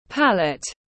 Cái bảng màu tiếng anh gọi là palette, phiên âm tiếng anh đọc là /ˈpæl.ət/
Palette /ˈpæl.ət/